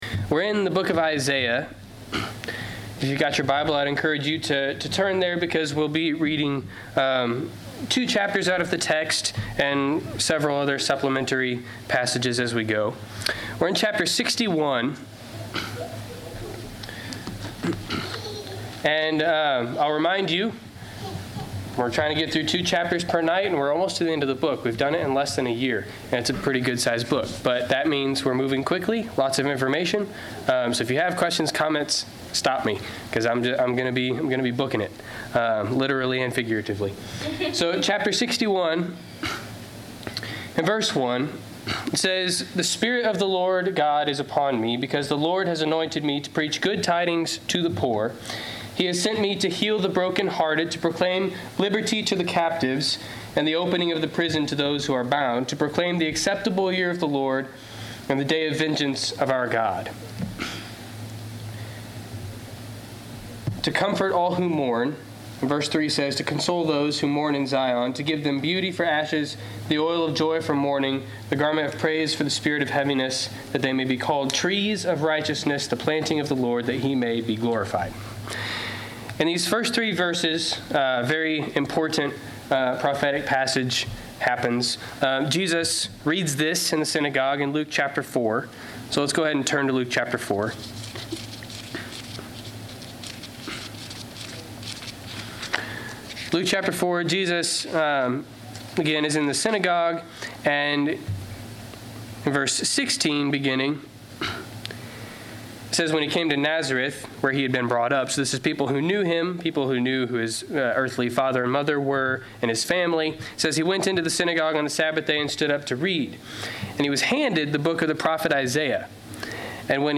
Isaiah 61-62 Service Type: Wednesday Night Class Download Files Notes Notes Notes Topics